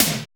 ENRGYTOM MD.wav